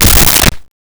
Creature Foot Step 03
Creature Foot Step 03.wav